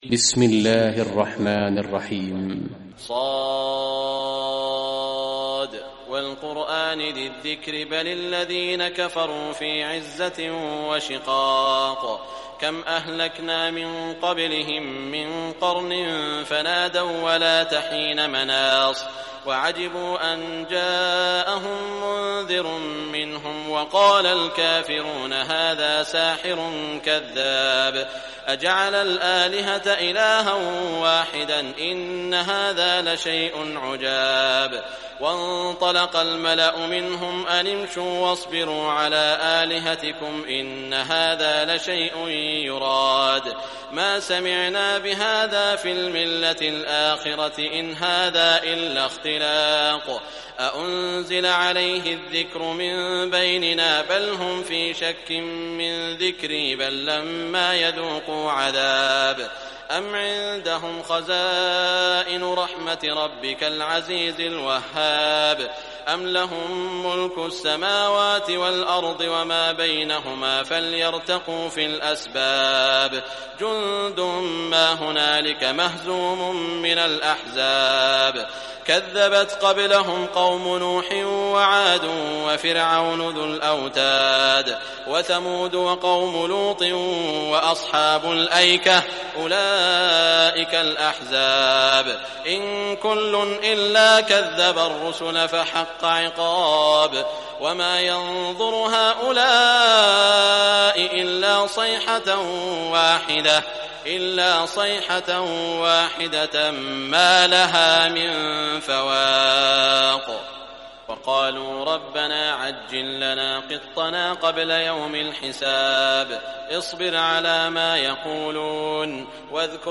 Surah Sad Recitation by Sheikh Shuraim
Surah Sad, listen or play online mp3 tilawat / recitation in the Arabic in the beautiful voice of Sheikh Saud Al Shuraim.